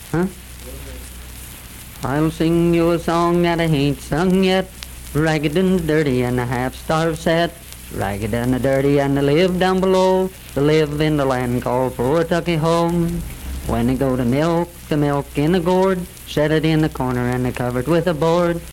Unaccompanied vocal music performance
Voice (sung)
Wirt County (W. Va.)